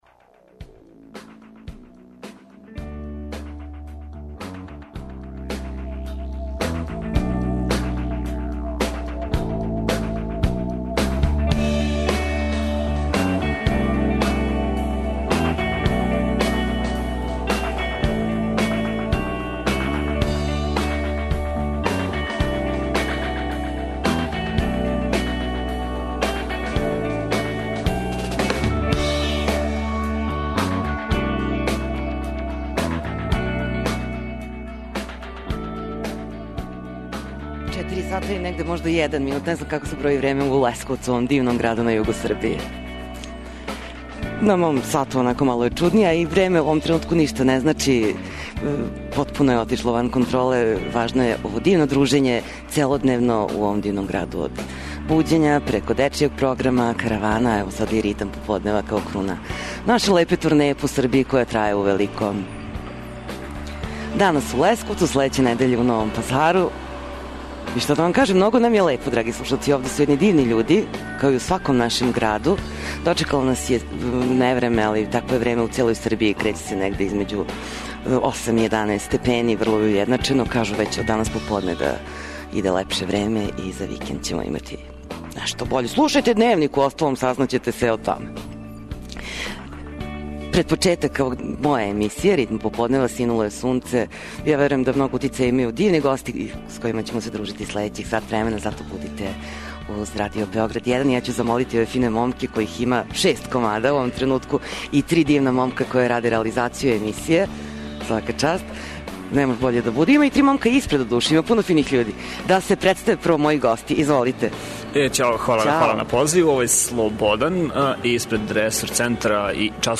Данашњу емисију реализујемо из Лесковца.